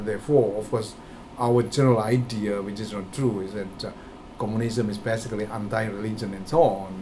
S2 = Indonesian male Context: S2 is talking about religion and communism. S2 : ... thereFORE of course (.) our general IDea which is not true is that er (.) communism is basically anti religion and SO on Intended Word: anti Heard as: undie Discussion: S2 pronounces anti as [ʌntaɪ] .